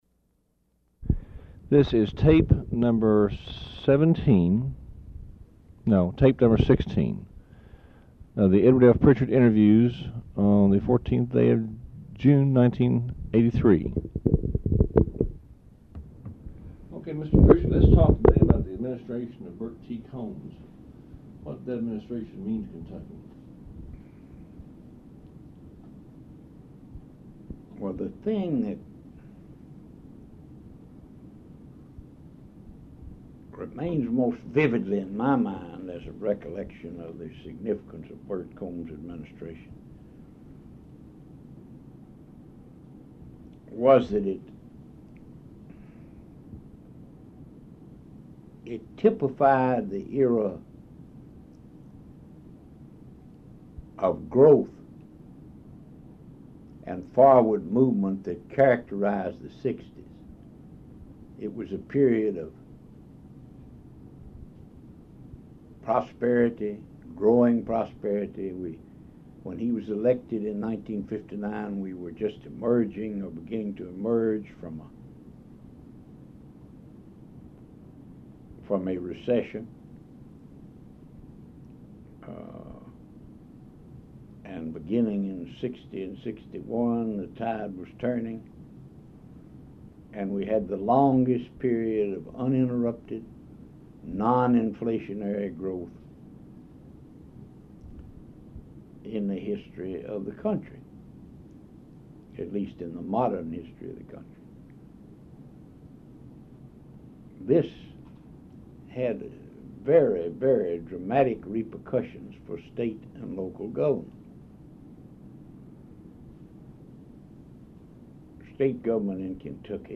Oral History Interview with Edward F. Prichard, Jr., June 14, 1983 Part 1